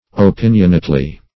opinionately - definition of opinionately - synonyms, pronunciation, spelling from Free Dictionary Search Result for " opinionately" : The Collaborative International Dictionary of English v.0.48: Opinionately \O*pin"ion*ate*ly\, adv.
opinionately.mp3